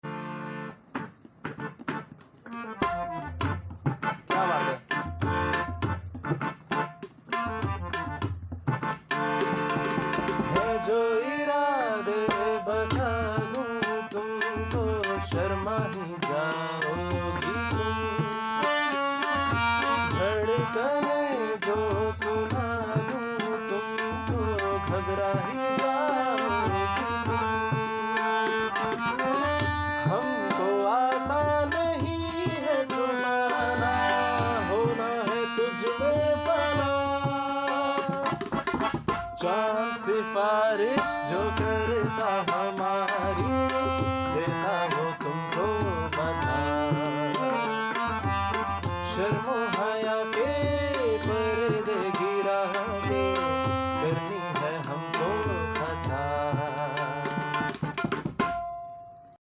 Raw Rehearsal Jam Session For Sound Effects Free Download